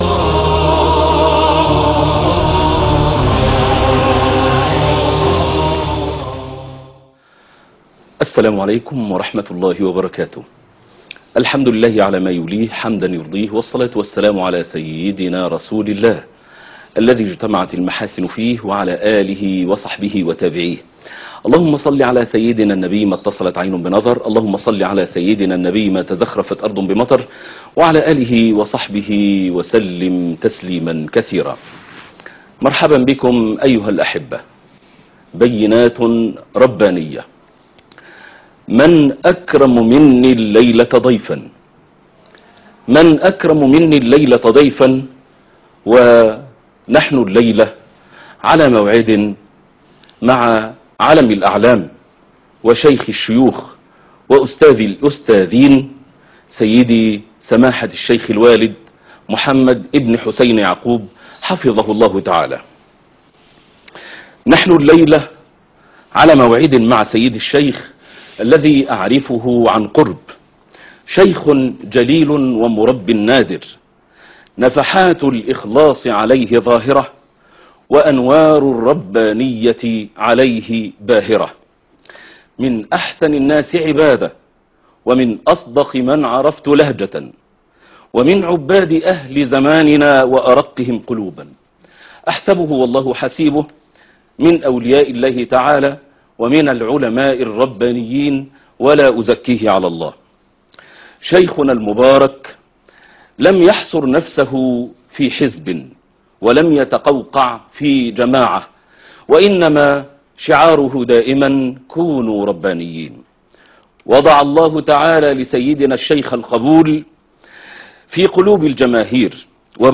بينات ربانية ( 1/11/2025 ) سهرة ربانية لقاء خاص مع الشيخ محمد حسين يعقوب - فضيلة الشيخ محمد حسين يعقوب